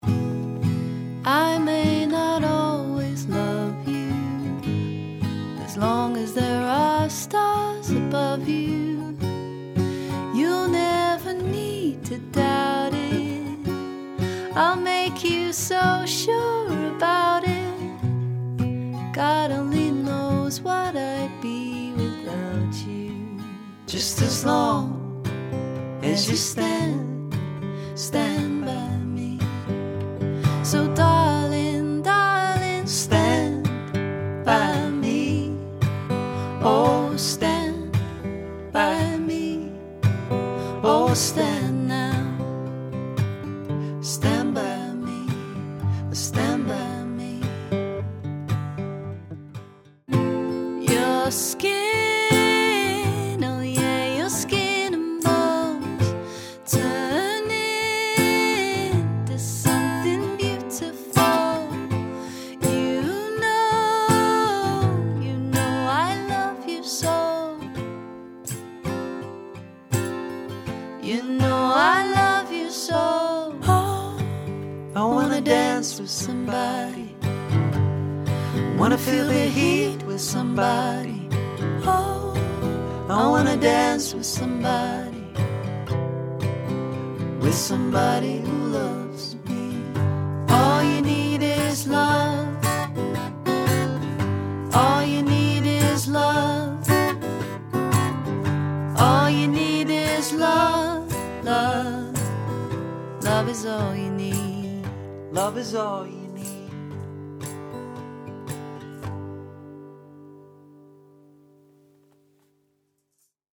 • Sophisticated cover versions of popular songs